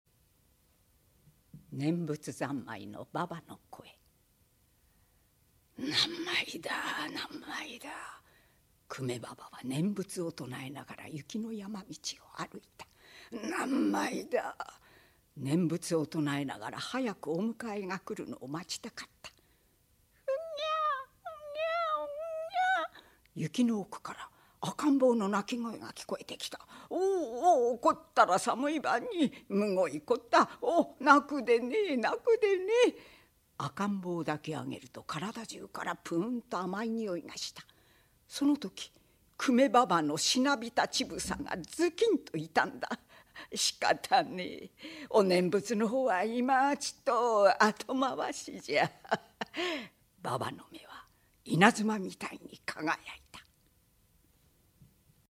老婆
ボイスサンプル